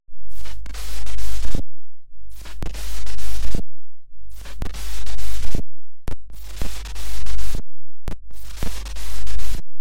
Звуки зависания, сбоя программы
Звук микрофона залип